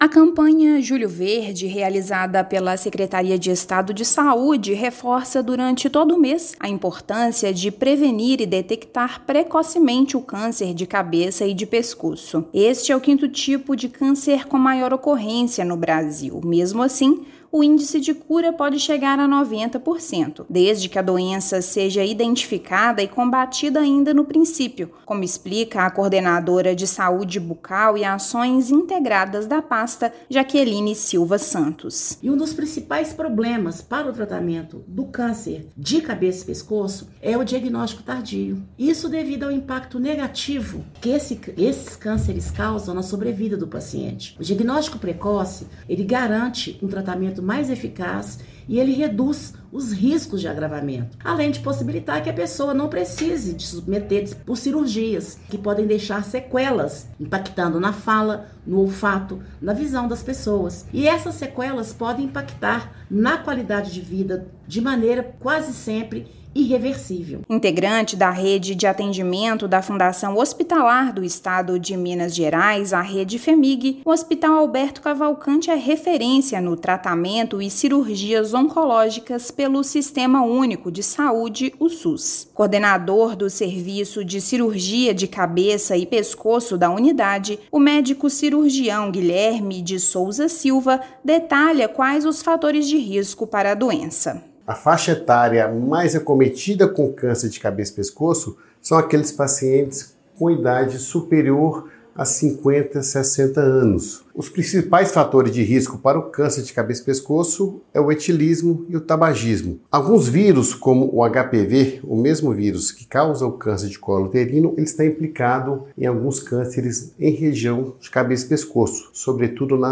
Hospital Alberto Cavalcanti é referência estadual no tratamento e cirurgias oncológicas pelo Sistema Único de Saúde. Ouça matéria de rádio.